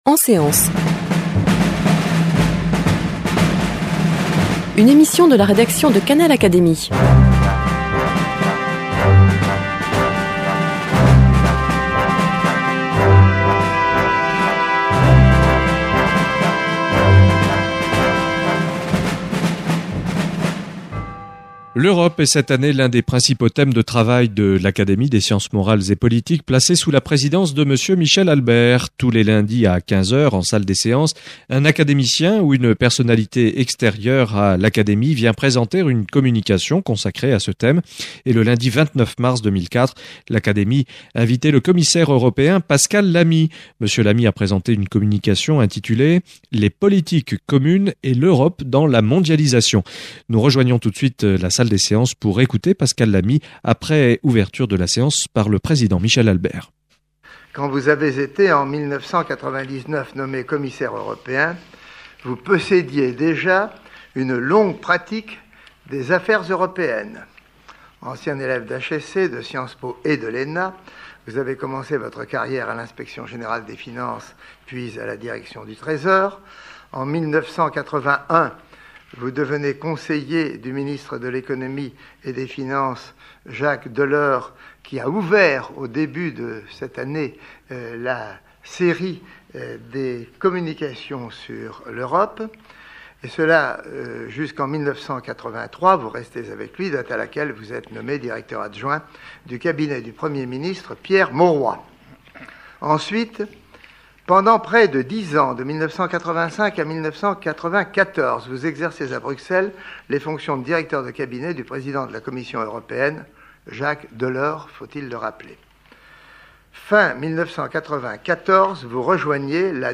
Communication de Pascal Lamy, Directeur général de l’OMC, prononcée en séance publique devant l’Académie des sciences morales et politiques le lundi 29 mars 2004.